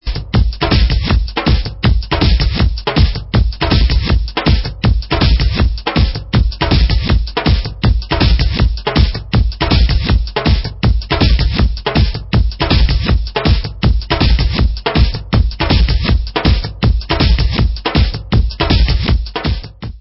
Rhythm Pattern 3